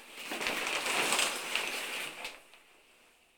showercurtainclose.ogg